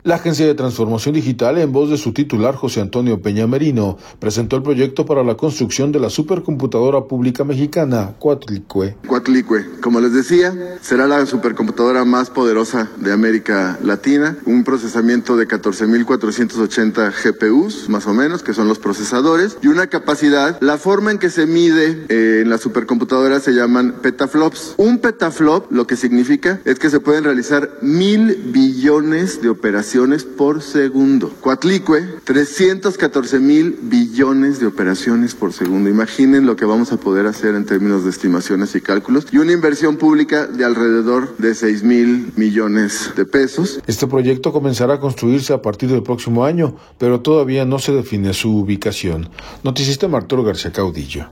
La Agencia de Transformación Digital en voz de su titular, José Antonio Peña Merino, presentó el proyecto para la construcción de la súper computadora pública mexicana Coatlicue.